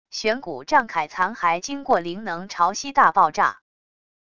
玄骨战铠残骸经过灵能潮汐大爆炸wav音频